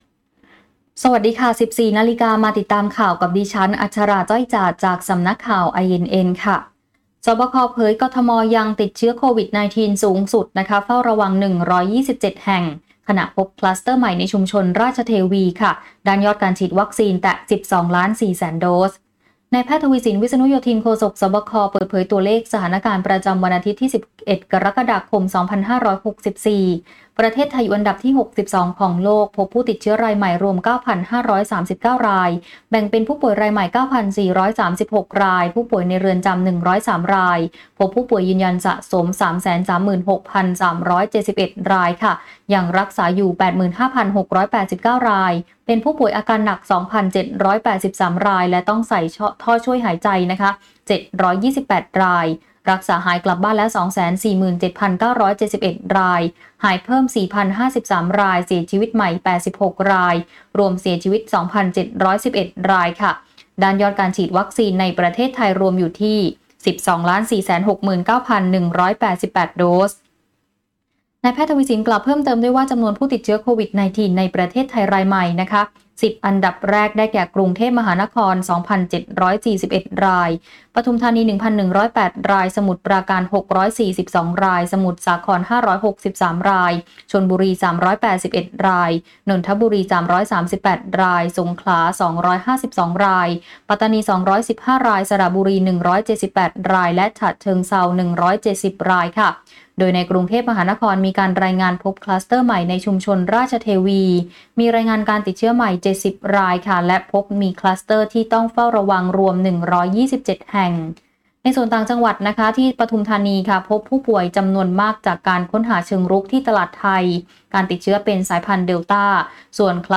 คลิปข่าวต้นชั่วโมง
ข่าวต้นชั่วโมง 14.00 น.